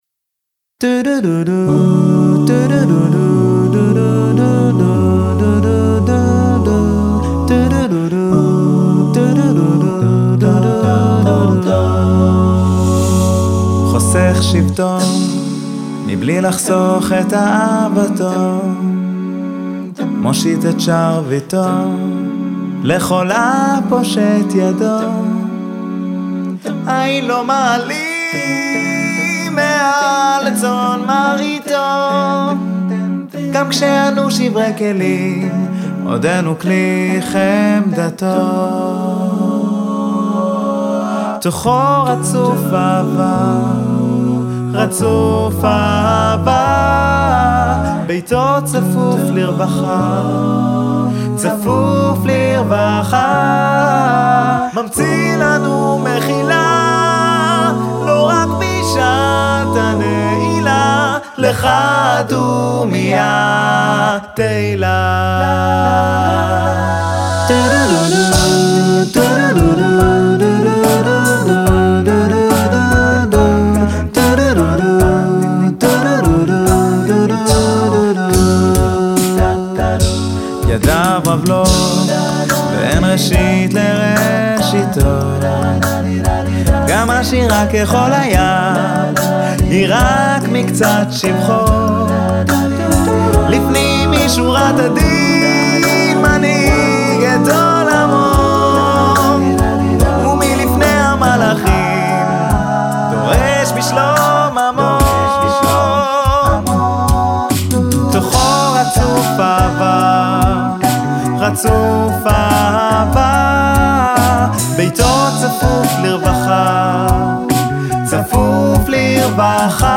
ביצוע נקי, מצלול מיוחד, עיבוד נהדר,
הכל קולות מקוריים, כמובן שכמו בכל שיר המיקס משפר את הסאונד.
יש פה עושר של קולות נמוכים...
האיזון בין הקולות מדהים וממש לא חסר בס.